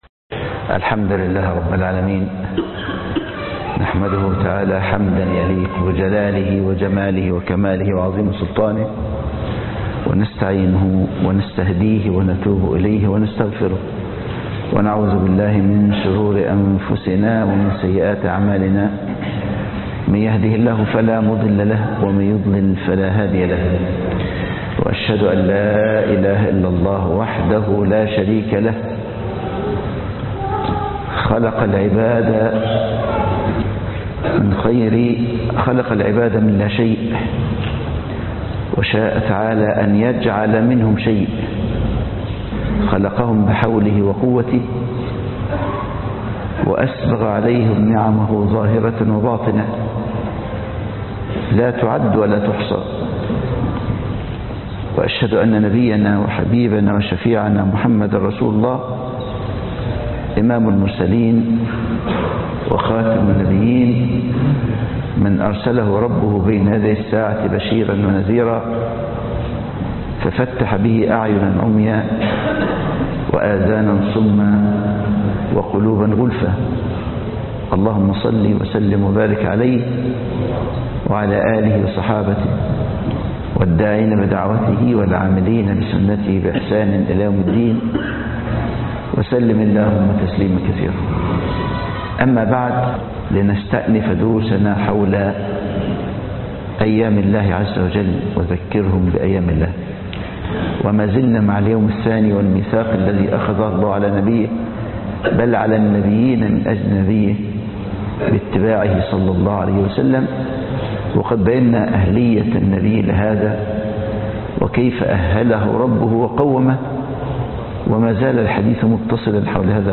الوحي والتربية اليرموك (مسجد العطية)